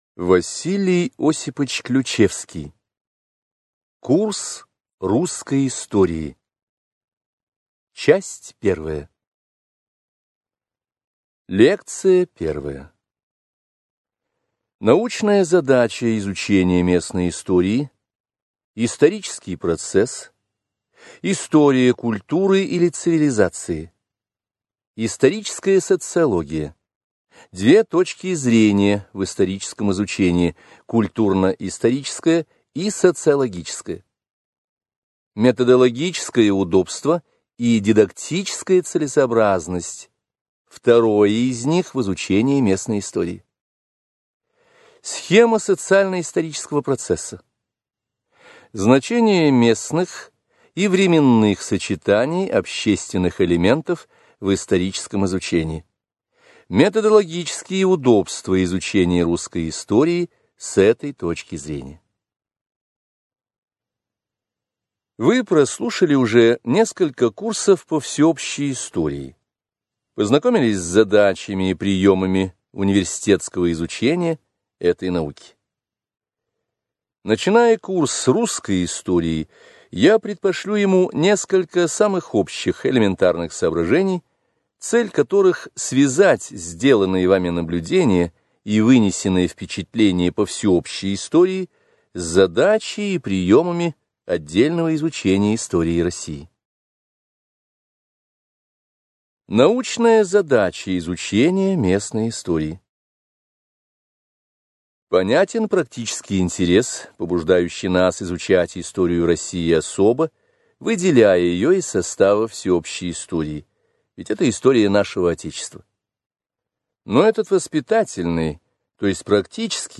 Аудиокнига Курс русской истории в 5-ти частях | Библиотека аудиокниг